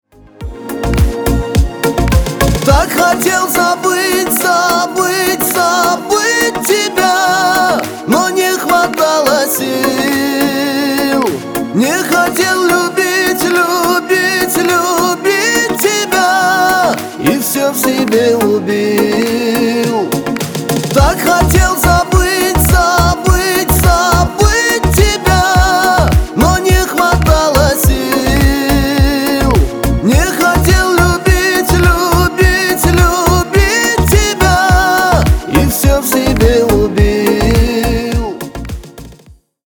Поп Музыка # грустные